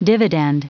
Prononciation du mot dividend en anglais (fichier audio)
Prononciation du mot : dividend